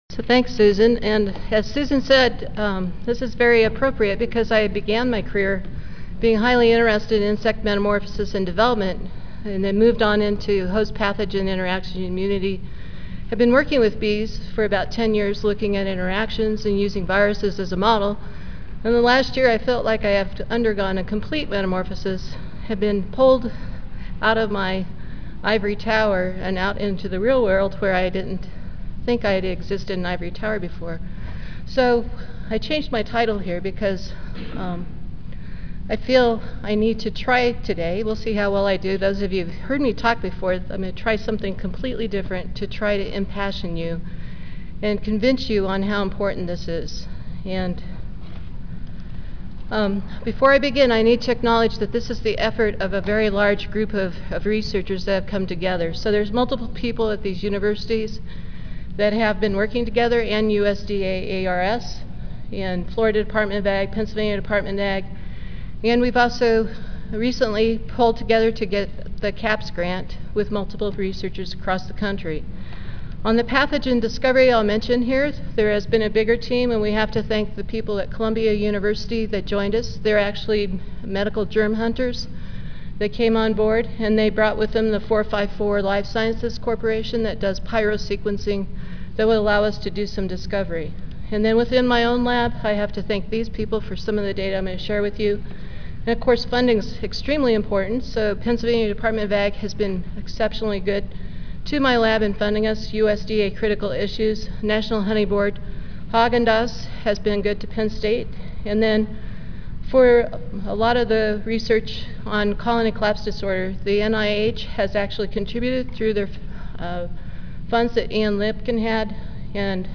Room A3, First Floor (Reno-Sparks Convention Center)